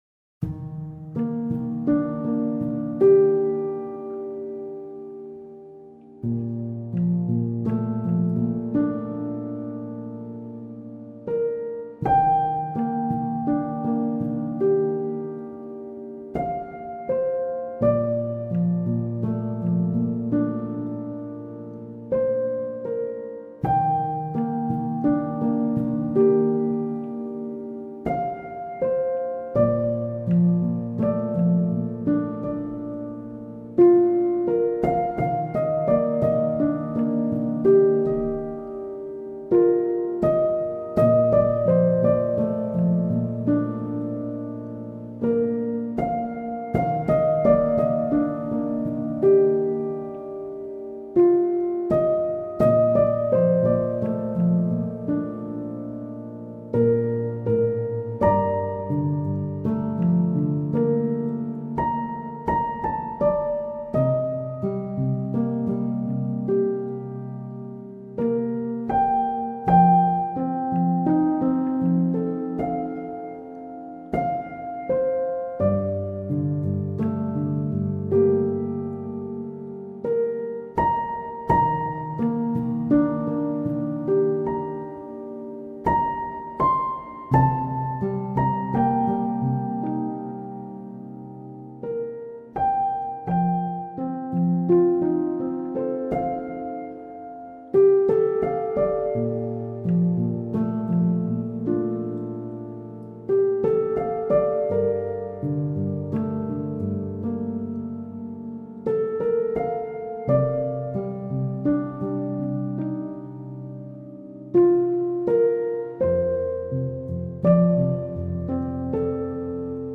آرامش بخش , پیانو , عصر جدید , موسیقی بی کلام
پیانو آرامبخش